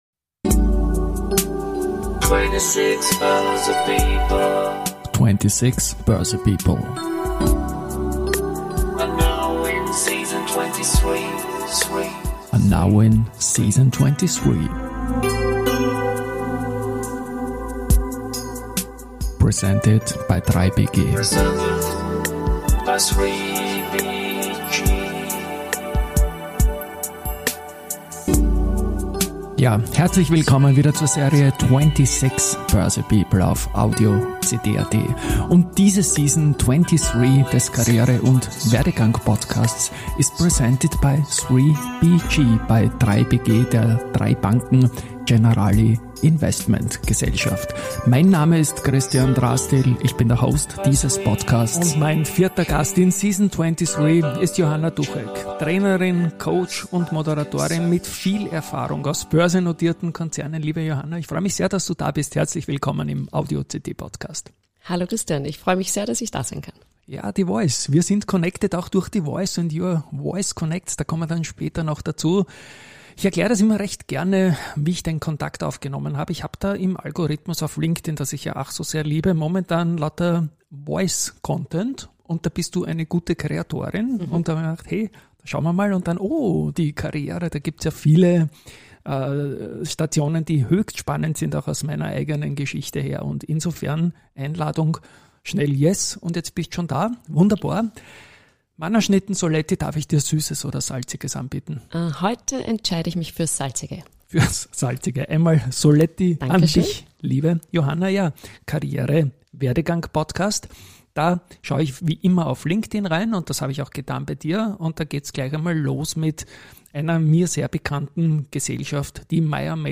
Es handelt sich dabei um typische Personality- und Werdegang-Gespräche.